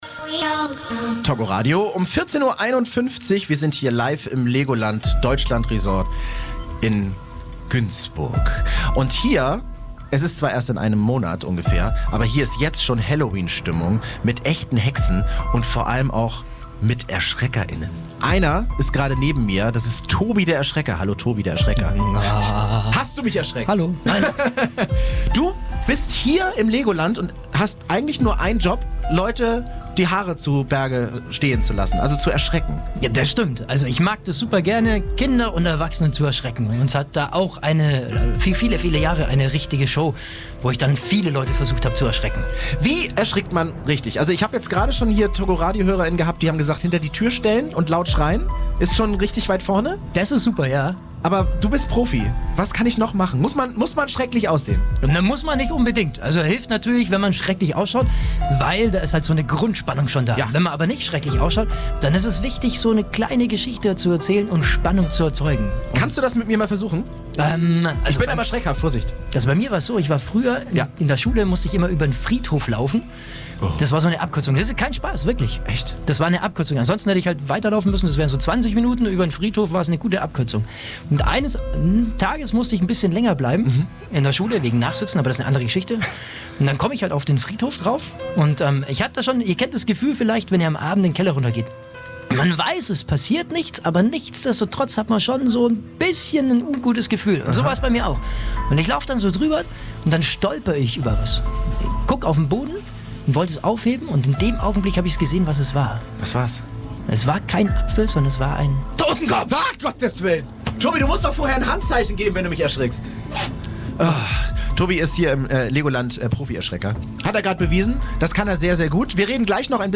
Erschrecker-Experte